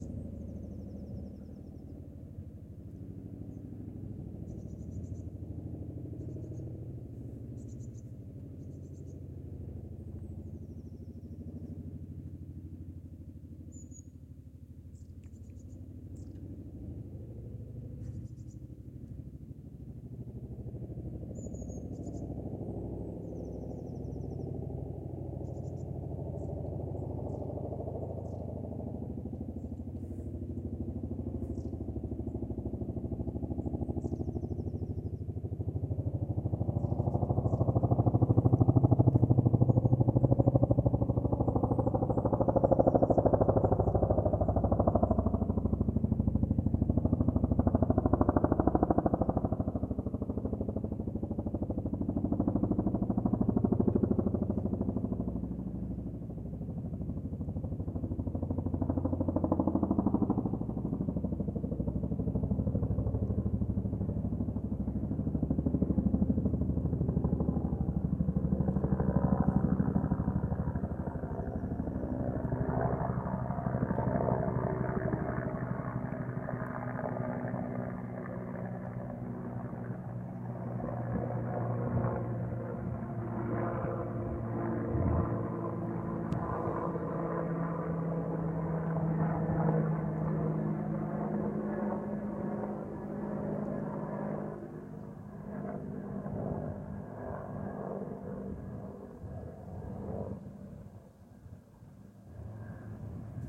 "쿵" 하는 소리, 오스트리아 UH-1
이러한 결합된 감속은 메인 로터에서 324rpm을 생성한다.[24] 프리-콘드 및 언더슬렁 블레이드를 갖춘 2엽 반강성 로터 설계[25]는 벨 47과 같은 초기 벨 모델 설계의 발전으로, 감쇠 안정 장치와 같은 공통 설계 기능을 공유한다. 2엽 시스템은 항공기가 필요로 하는 저장 공간을 줄이지만, 더 높은 진동 수준이라는 비용이 발생한다. 2엽 설계는 또한 항공기가 비행 중일 때 특징적인 '휴이 쿵' 소리[26][27]를 발생시키는데, 이는 특히 하강 중과 선회 비행 중에 두드러진다.